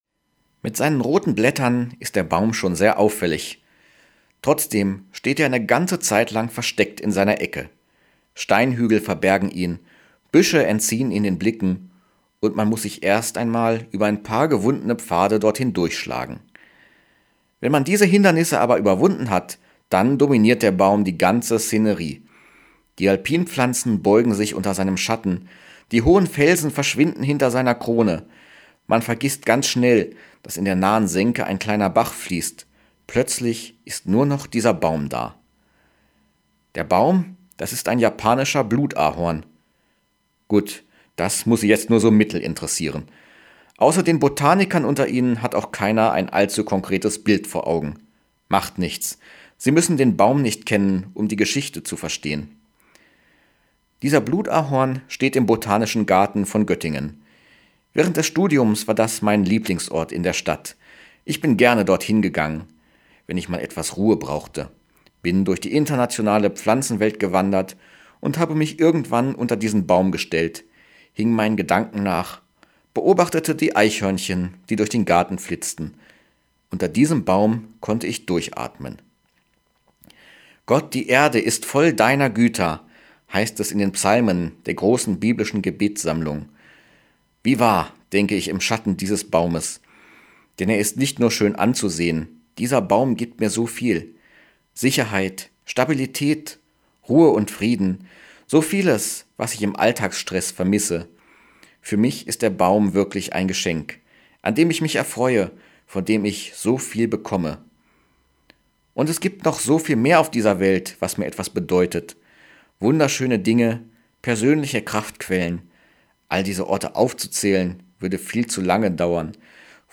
Radioandacht vom 31. Mai